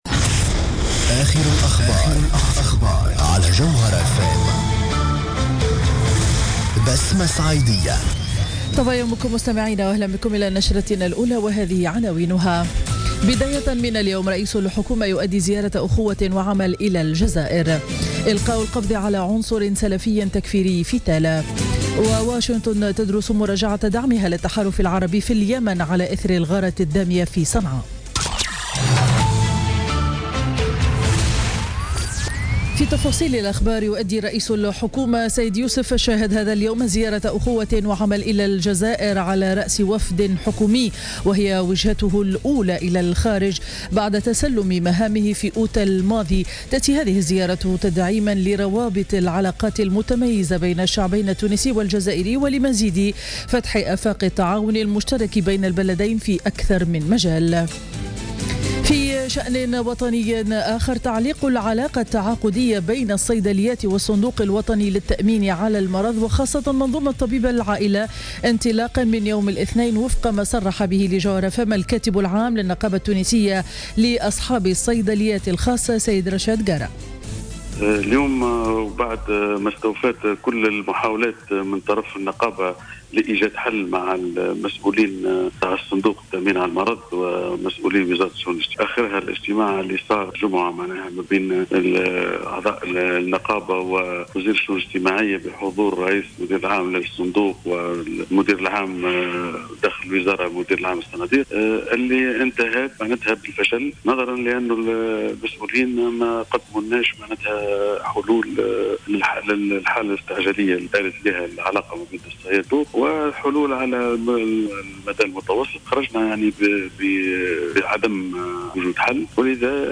نشرة أخبار السابعة صباحا ليوم الأحد 9 أكتوبر 2016